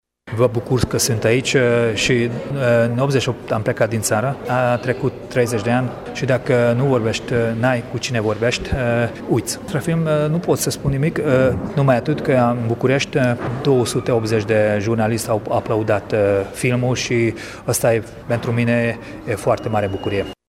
Prezent la premiera de la Palatul Culturii a filmului ”Banditul Whisky”, Ambrus Attila a declarat pentru Radio Tg.Mureş: